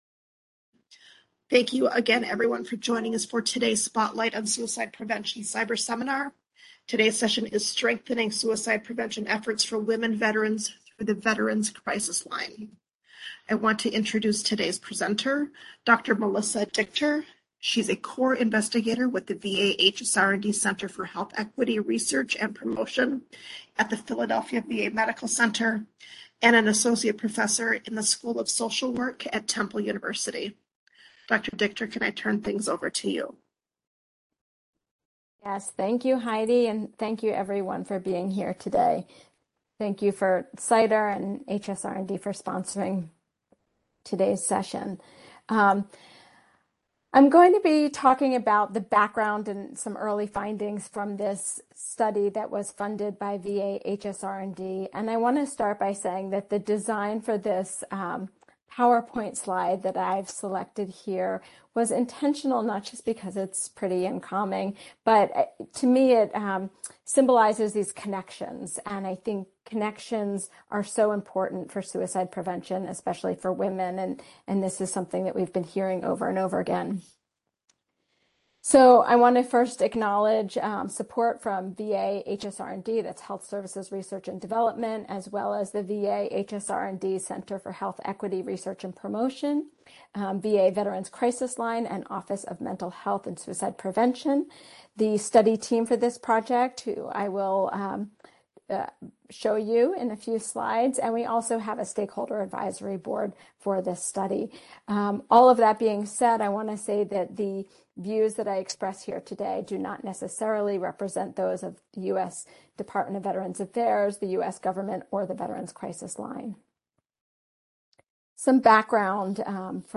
MSW Seminar date